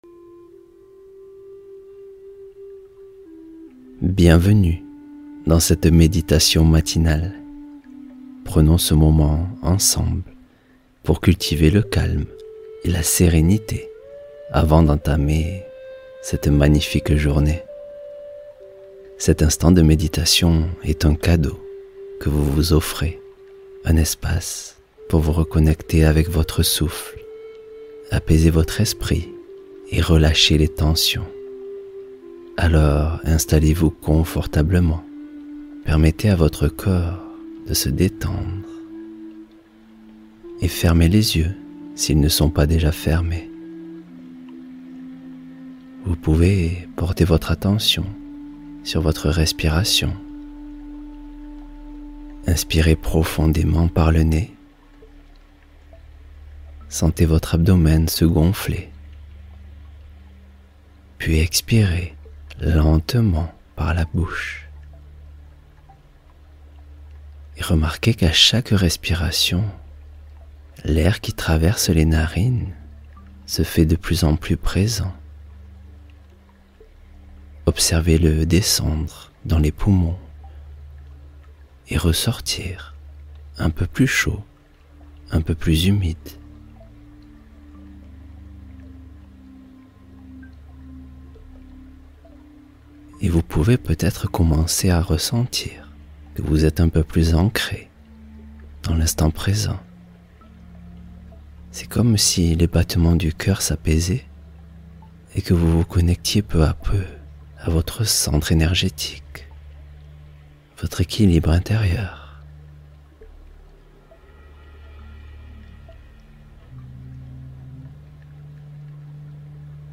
Lâcher-prise profond — Méditation pour apaiser tensions et nervosité